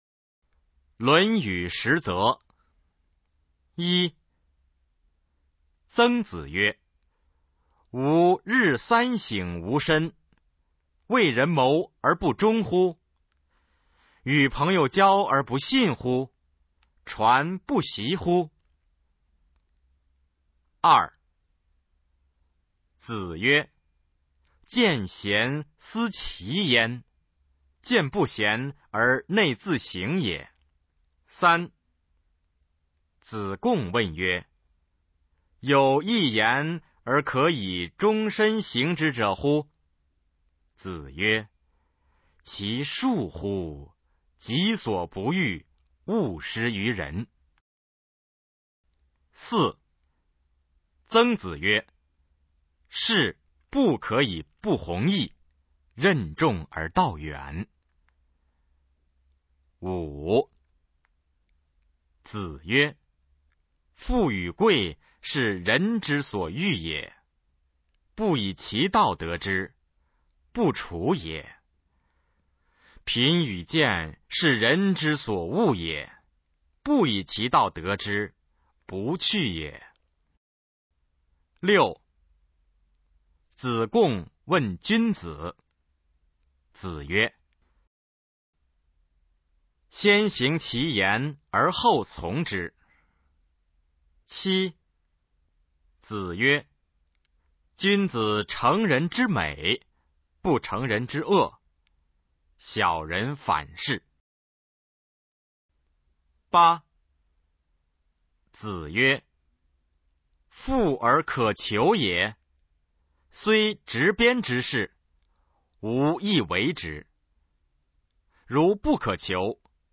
首页 视听 语文教材文言诗文翻译与朗诵 初中语文九年级上册